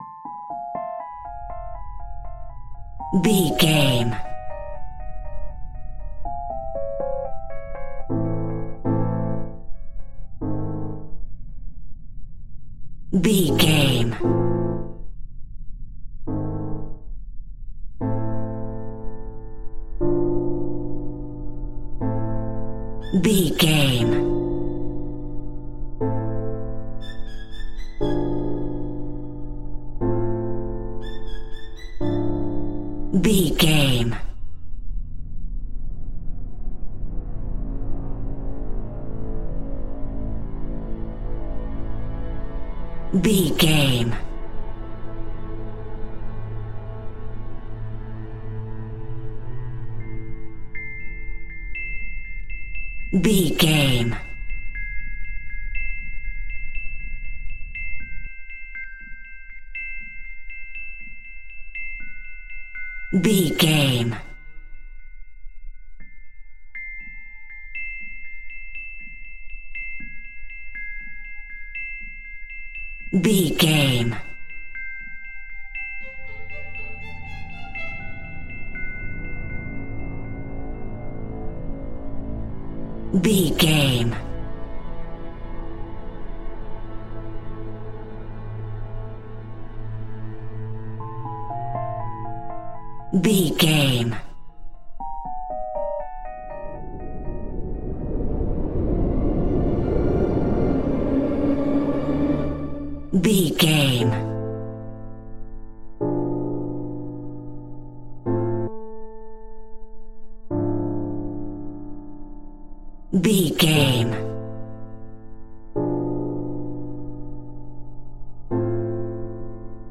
70s Style Horror Music.
Aeolian/Minor
tension
ominous
dark
suspense
eerie
strings
piano
synthesizer
pads